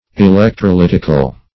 Meaning of electrolytical. electrolytical synonyms, pronunciation, spelling and more from Free Dictionary.
Search Result for " electrolytical" : The Collaborative International Dictionary of English v.0.48: Electrolytic \E*lec`tro*lyt"ic\, Electrolytical \E*lec`tro*lyt"ic*al\, a. [Cf. F. ['e]lectrolytique.]